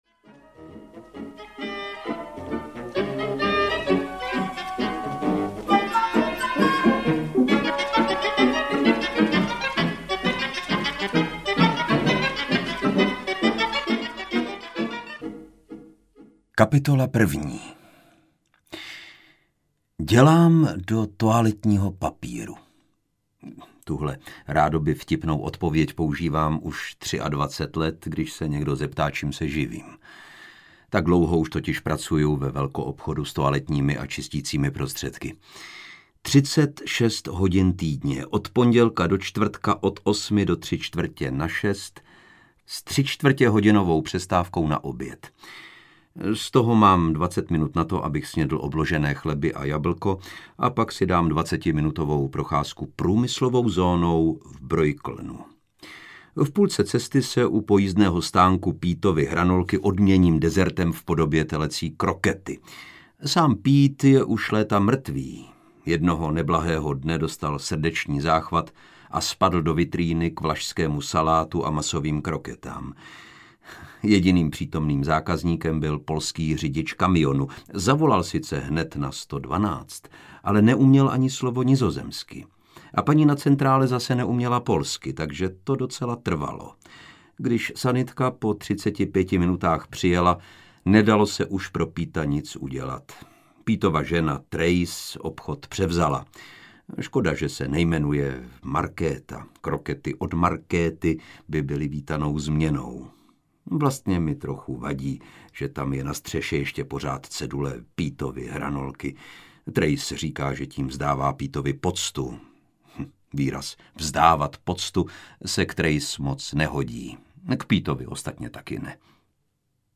Žít a nechat žít audiokniha
Ukázka z knihy
• InterpretLucie Juřičková, Lukáš Hlavica